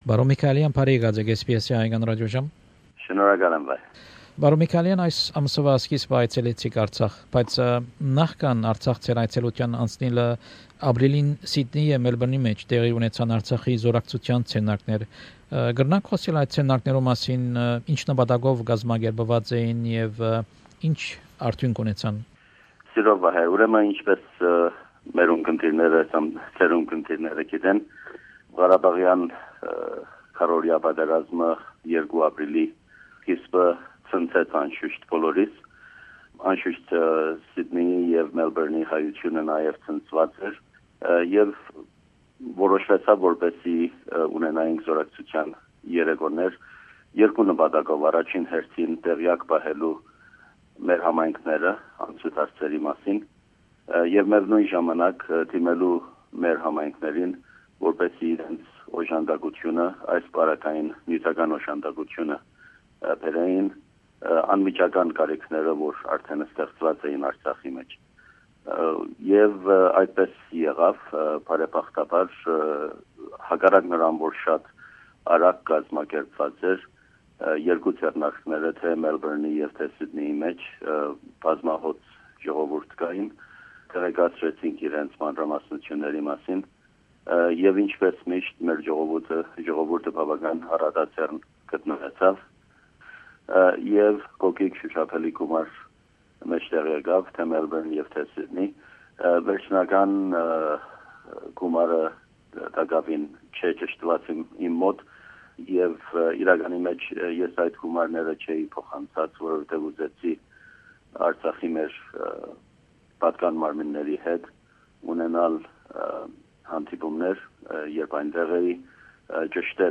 An interview with Mr Kaylar Michaelian, the Permanent Representative of Nagorno Karabakh Republic to Australia, about his recent trip to NKR.